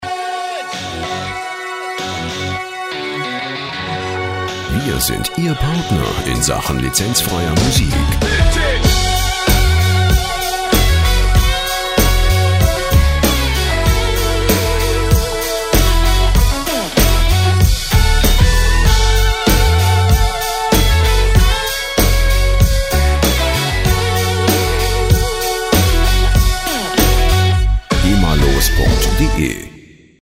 Show Opener
Musikstil: Vintage Rock
Tempo: 96 bpm